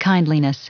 Prononciation du mot kindliness en anglais (fichier audio)
Prononciation du mot : kindliness